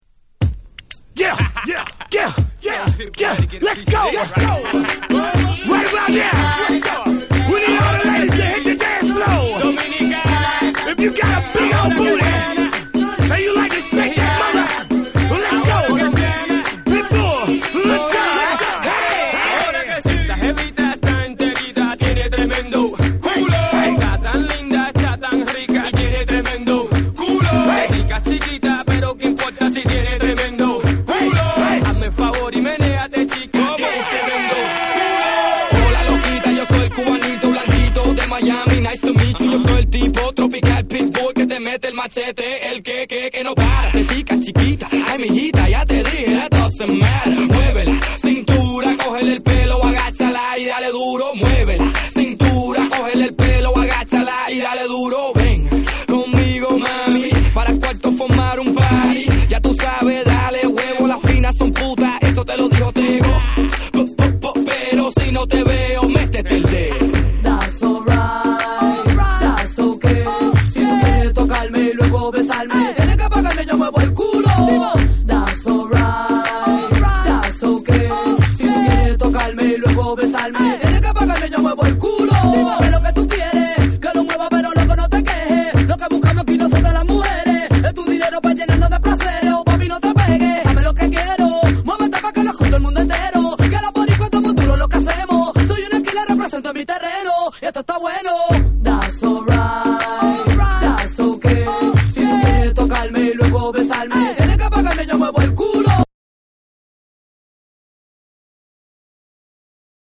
レゲトンのヒット・トラックをコンパイルしたブート盤。
BREAKBEATS / ALL 840YEN